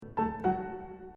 I have to apologize for the recording here, I haven’t gotten to the studio yet. So this is actually recorded at home, in my practicing studio. And as you can hear, I have practiced some, the piano is very out of tune.
Four more steps down.